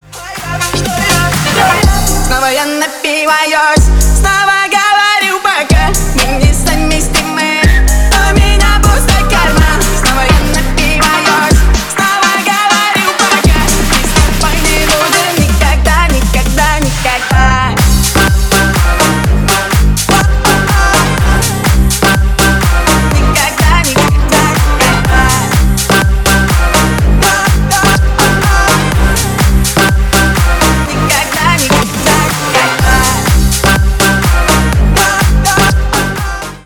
Ремикс
громкие